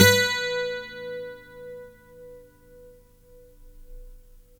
Index of /90_sSampleCDs/Roland LCDP02 Guitar and Bass/GTR_Steel String/GTR_12 String
GTR 12STR 06.wav